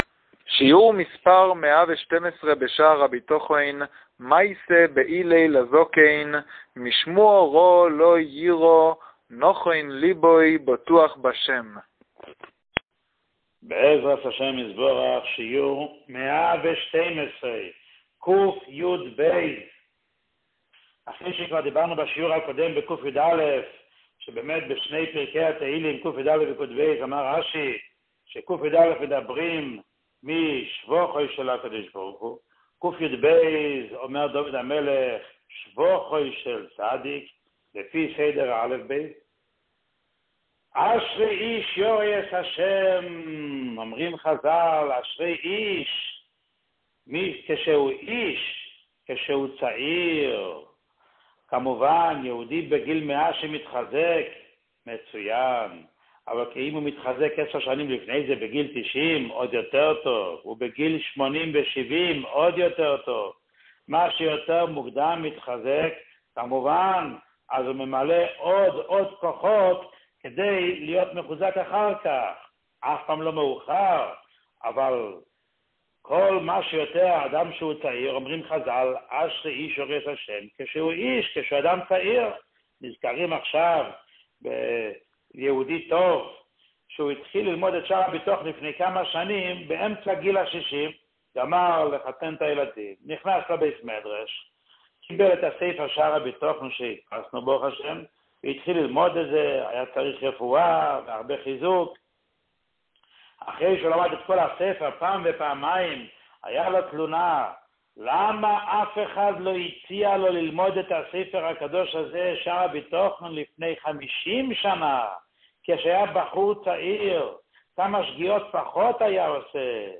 שיעור 112